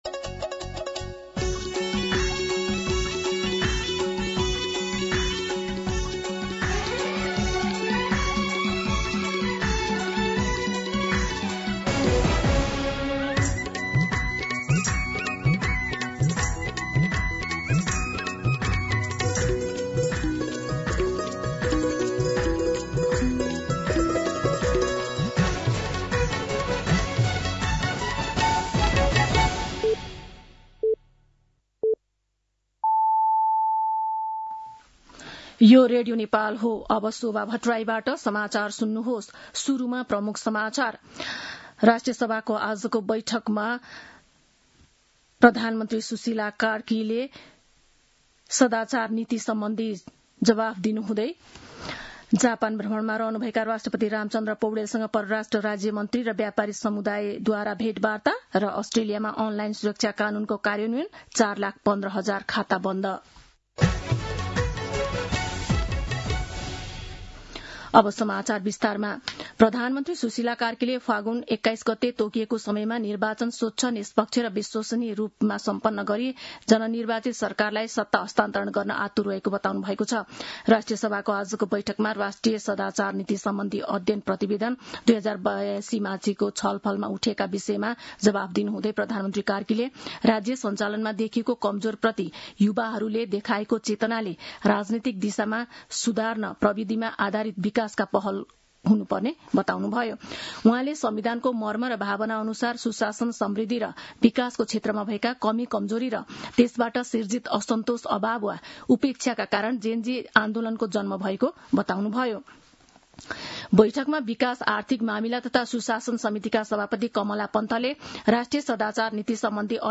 दिउँसो ३ बजेको नेपाली समाचार : १९ माघ , २०८२
3pm-News-19.mp3